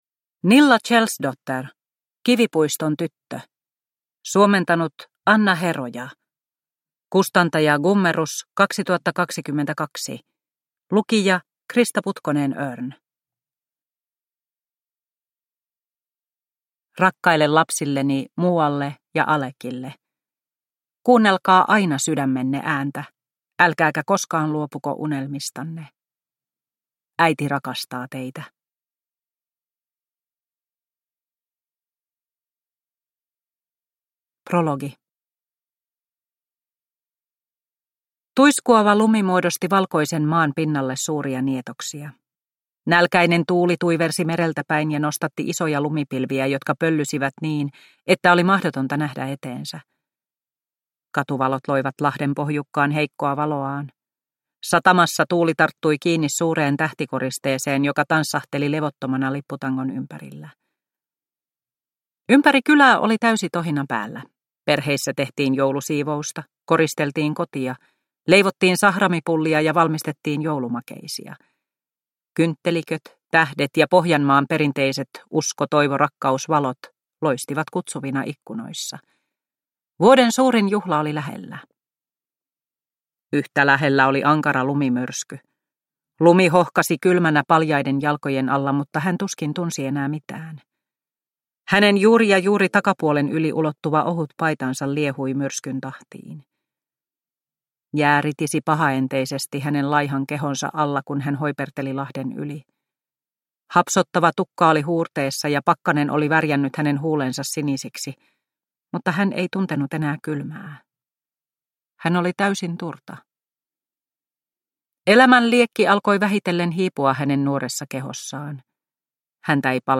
Kivipuiston tyttö – Ljudbok – Laddas ner